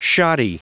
Prononciation du mot shoddy en anglais (fichier audio)
Prononciation du mot : shoddy